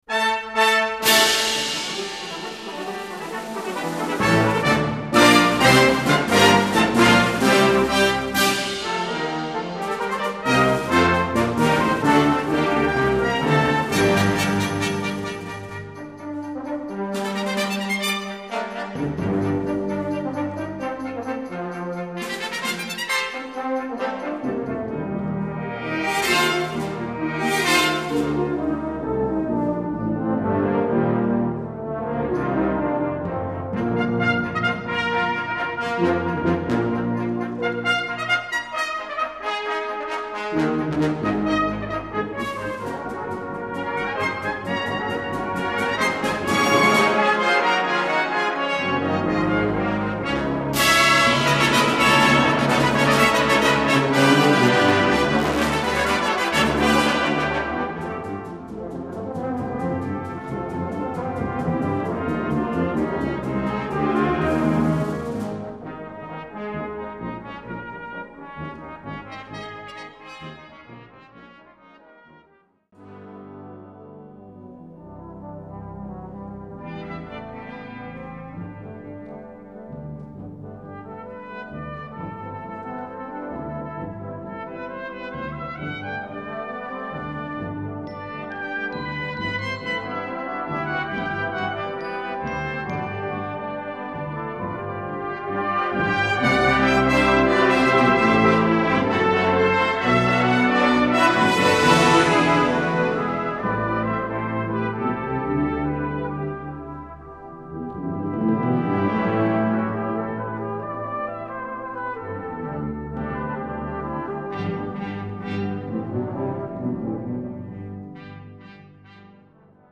Répertoire pour Brass band